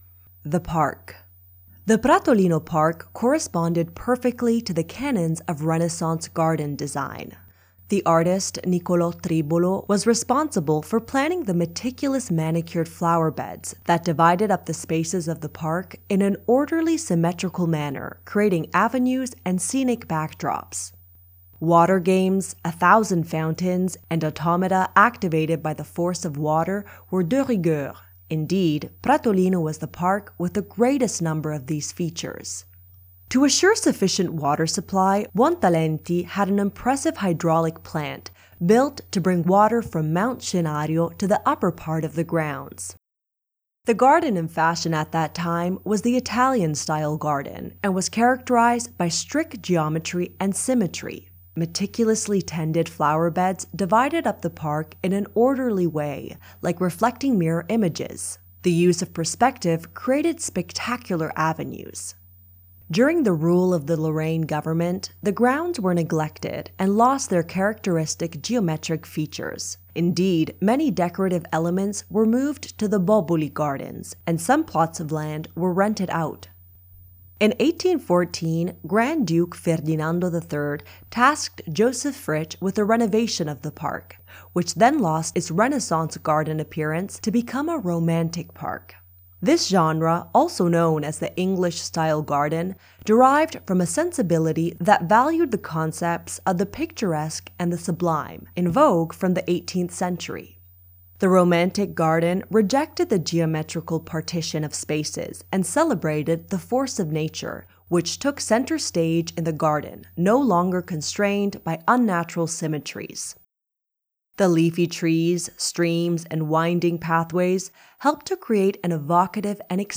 Audioguide of the Medici Park of Pratolino